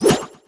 solgrenade01.wav